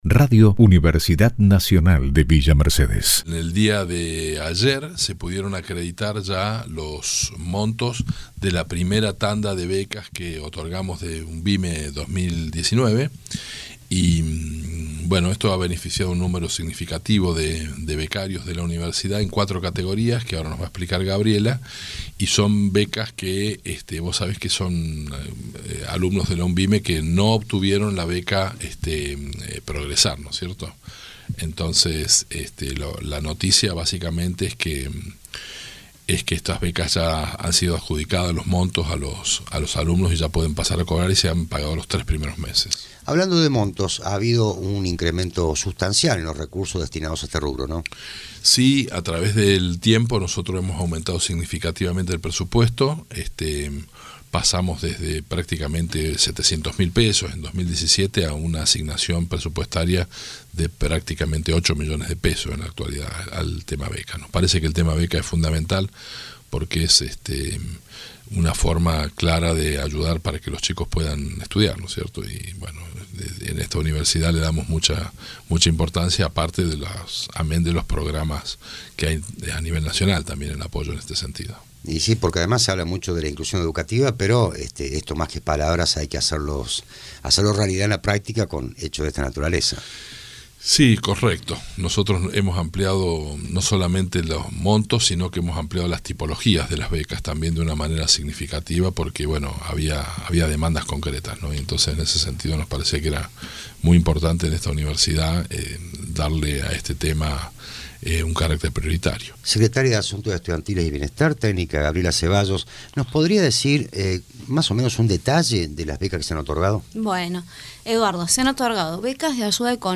Los funcionarios dialogaron con Radio UNViMe 97.3.